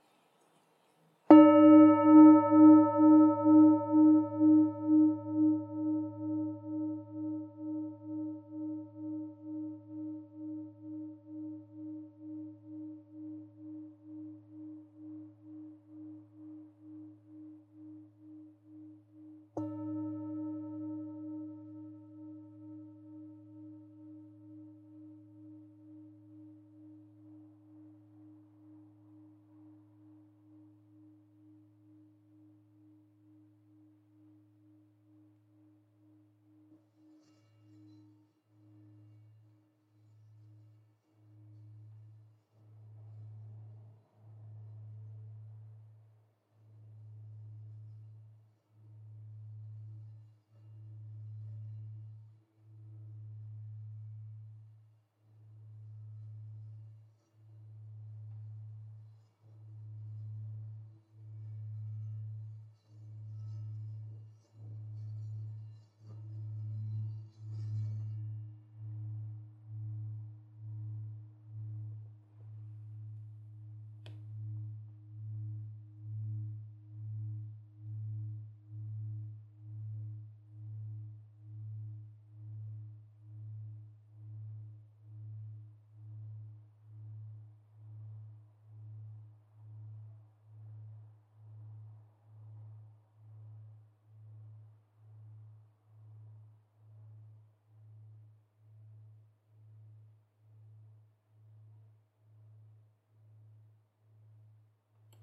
Campana Tibetana ULTA Nota SOL(G) d2 106 HZ -R003 per yoga e meditazione - Tra Cielo e Terra
Campana Tibetana Antica 100-300 anni, creata con l’antica tradizione tibetana in lega dei 7 metalli.
Nota Armonica    DO(C) d3 140 HZ
Nota di fondo      SOL(G) d2 106 HZ